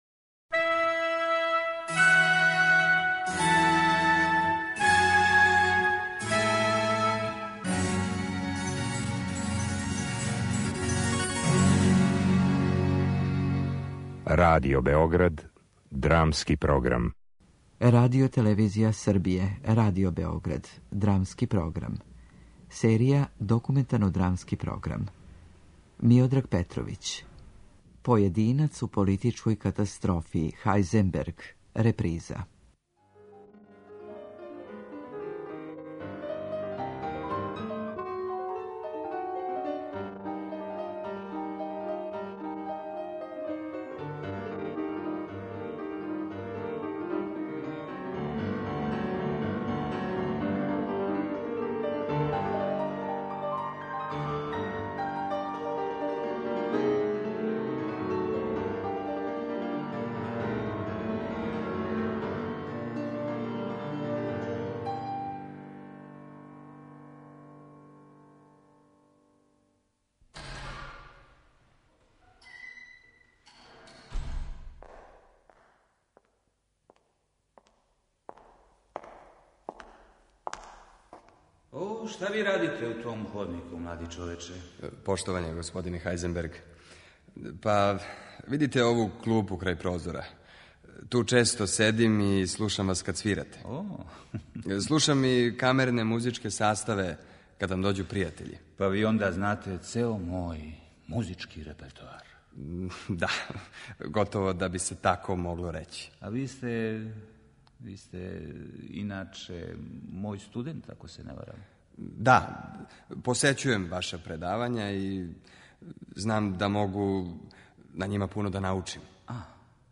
Документарно-драмски програм